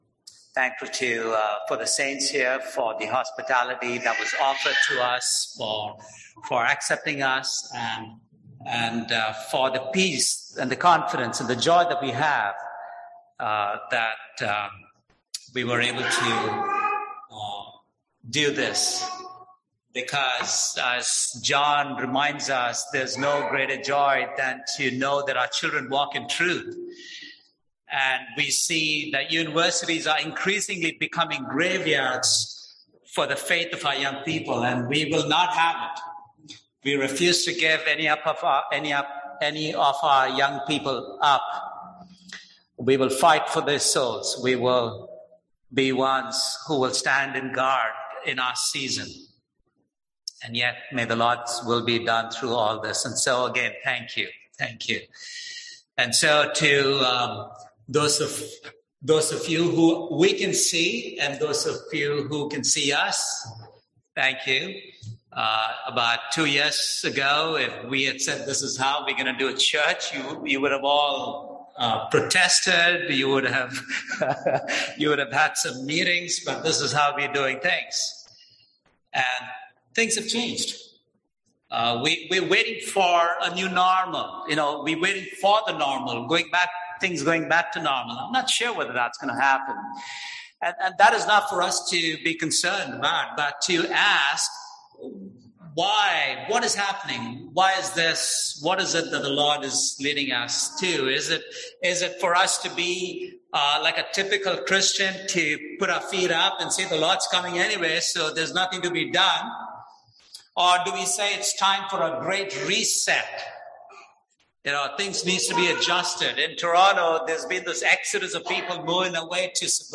Passage: Matthew 11:28-30 Service Type: Sunday AM Topics: God's Heart